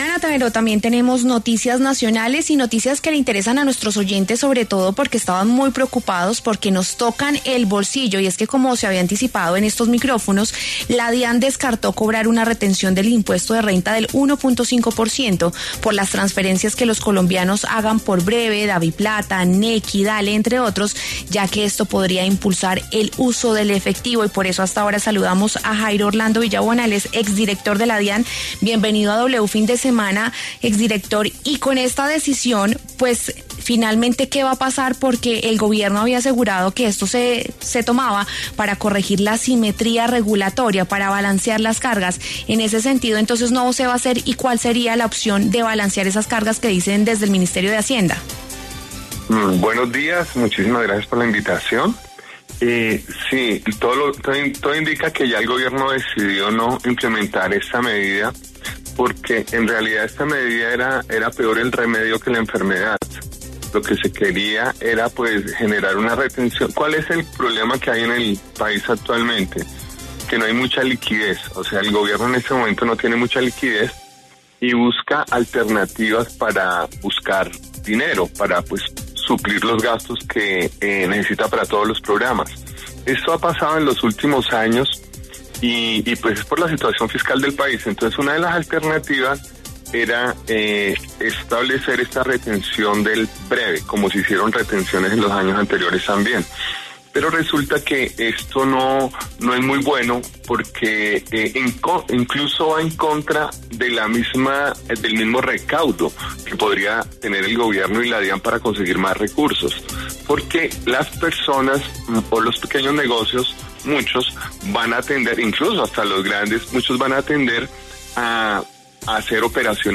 El exfuncionario Jairo Villabona se refirió en W Fin de Semana a la posibilidad, ya descartada, de cobrar retefuente por pagos con tarjetas vía datáfono y con Bre-B.